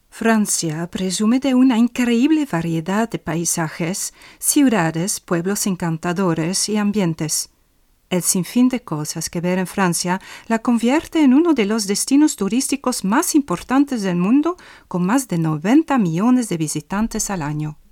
Vous pourrez compter sur un enregistrement impeccable de la voix néerlandaise flamande (belge), la voix française, la voix anglaise ou la voix espagnole grâce à l’équipement professionnel dont je dispose.
Voix off en Espagnol 🇲🇽